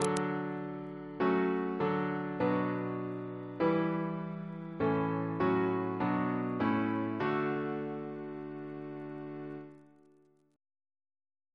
Single chant in D Composer: C. Hylton Stewart (1884-1932), Organist of Rochester and Chester Cathedrals, and St. George's, Windsor Reference psalters: ACP: 88; H1982: S262